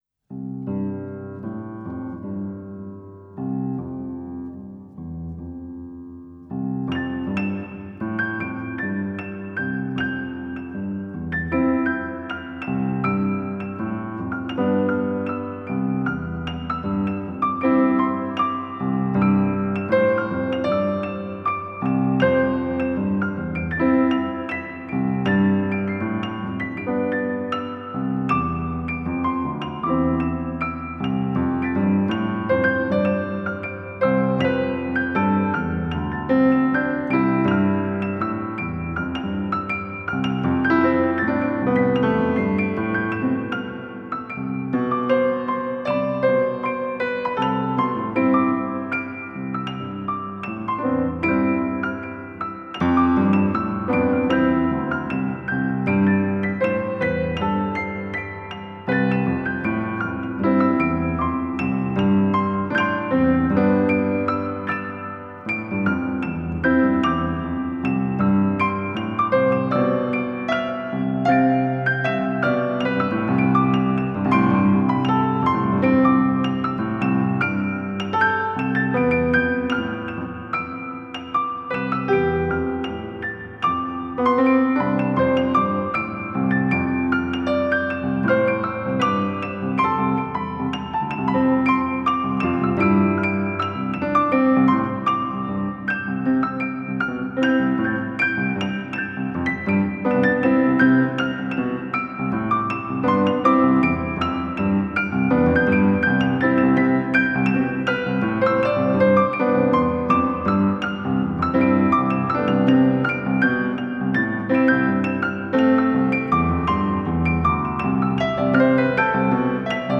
Jazz › Slow › World Music